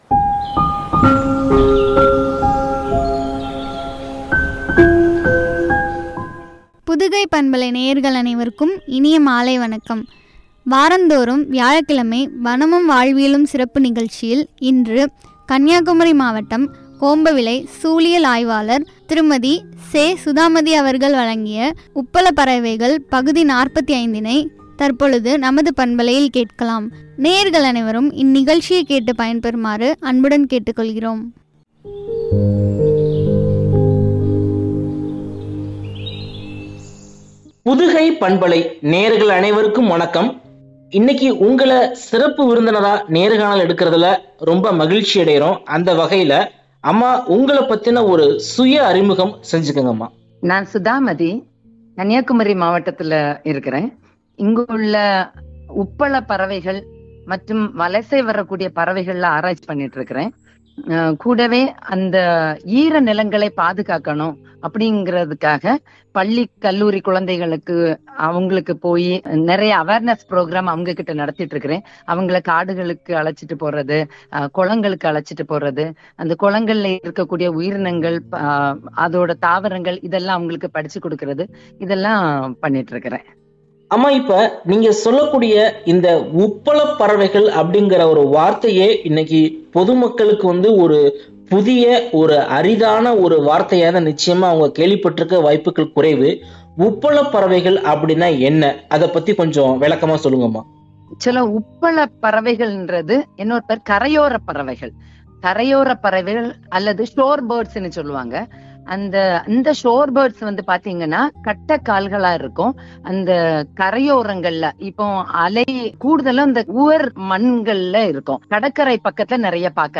உரை.